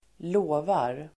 Uttal: [²l'å:var]